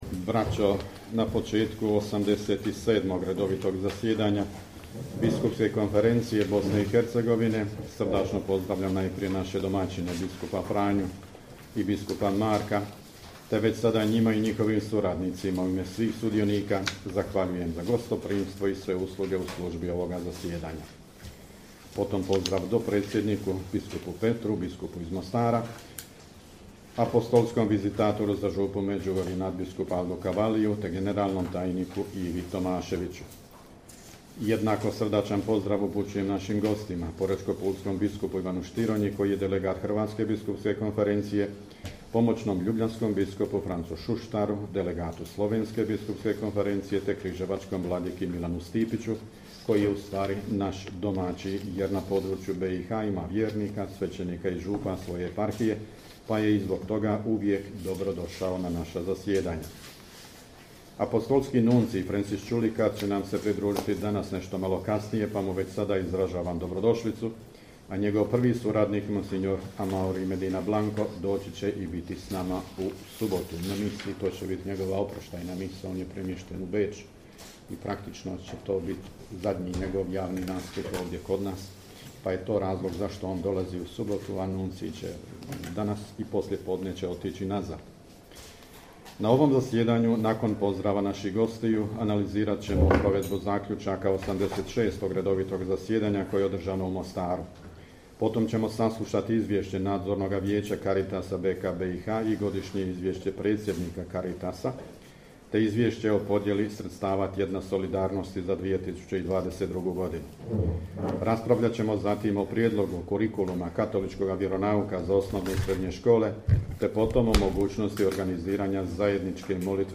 AUDIO/VIDEO: UVODNI POZDRAV NADBISKUPA VUKŠIĆA NA POČETKU 87. ZASJEDANJA BISKUPSKE KONFERENCIJE BOSNE I HERCEGOVINE
Na početku 87. redovitog zasjedanja Biskupske konferencije Bosne i Hercegovine, koje je započelo s radom u četvrtak, 13. srpnja 2023. u prostorijama Biskupskog ordinarijata u Banjoj Luci, nadbiskup metropolit vrhbosanski i apostolski upravitelj Vojnog ordinarijata u BiH mons. Tomo Vukšić, predsjednik BK BiH, uputio je uvodni pozdrav koji prenosimo u cijelosti: